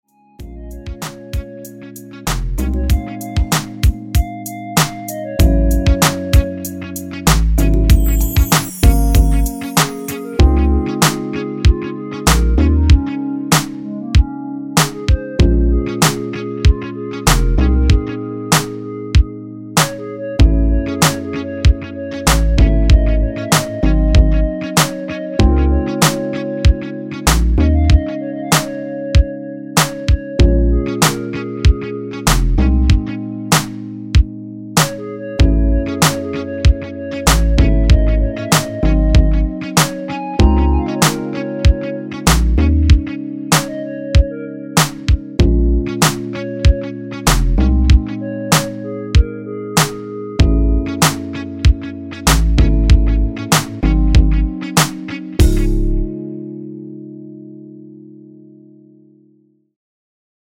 엔딩이 페이드 아웃이라 라이브 하시기 좋게 엔딩을 만들어 놓았습니다.(미리듣기 참조)
원키에서(-1)내린 멜로디 포함된 MR입니다.
Db
앞부분30초, 뒷부분30초씩 편집해서 올려 드리고 있습니다.